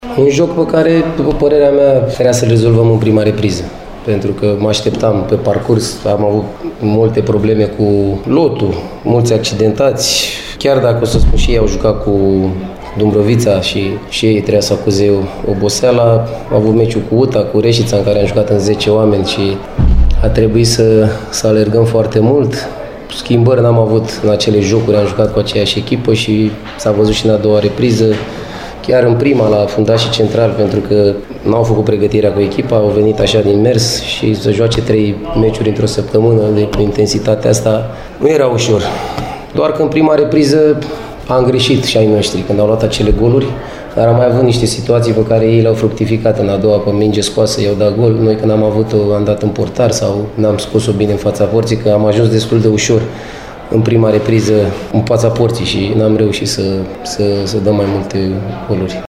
De cealaltă parte, antrenorul Stelei, Daniel Opriția, a vorbit și despre oboseala acumulată de echipa sa, care a disputat trei jocuri în decurs de 7 zile: